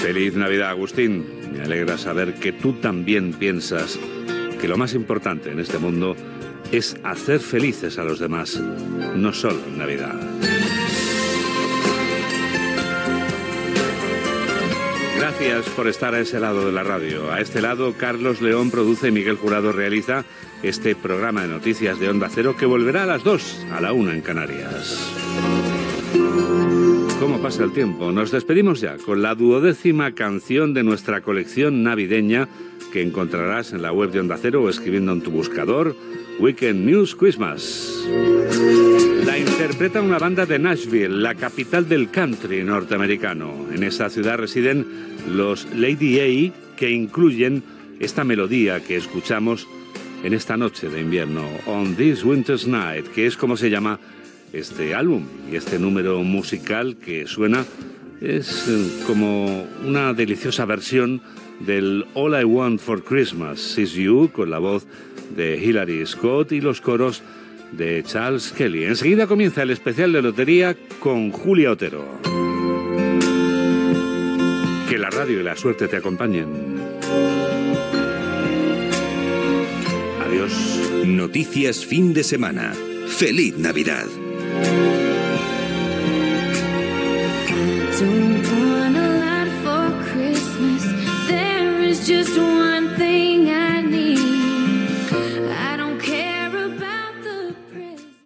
Comiat del programa, equip i tema musical
Informatiu